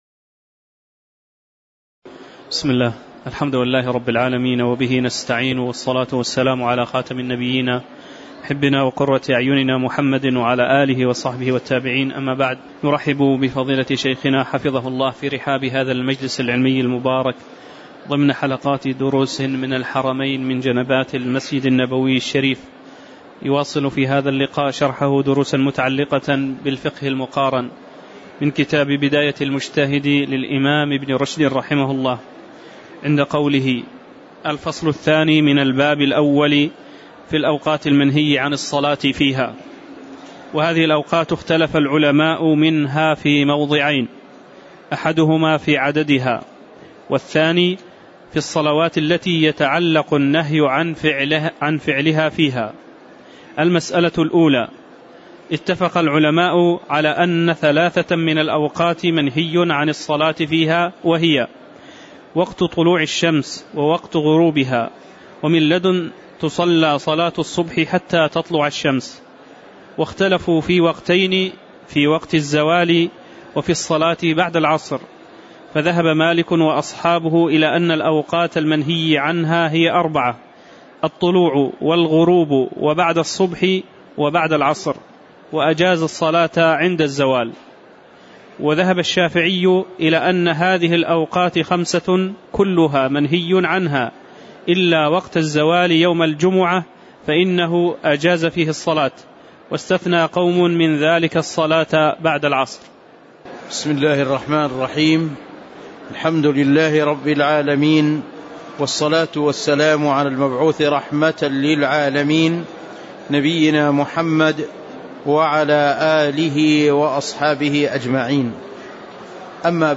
تاريخ النشر ٢١ صفر ١٤٤١ هـ المكان: المسجد النبوي الشيخ